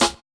130UKSNAR3-R.wav